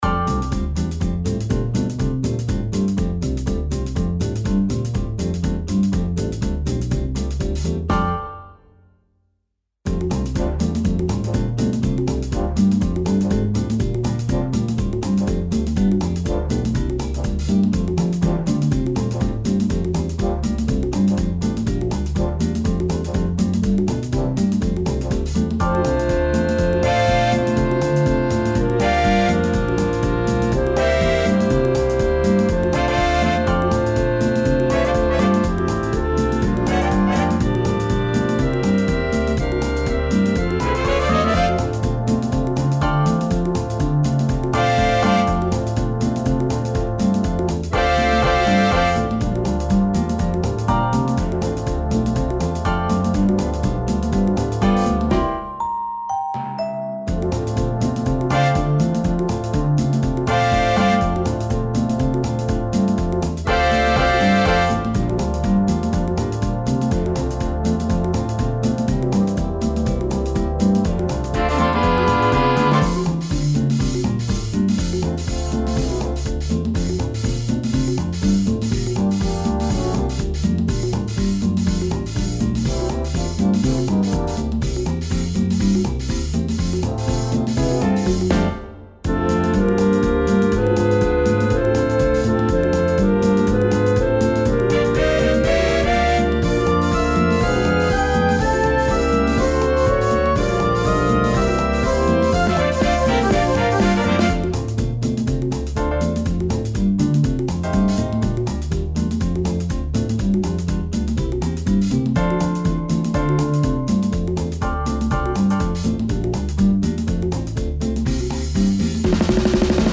Gattung: Weihnachtstitel für Blasorchester
Besetzung: Blasorchester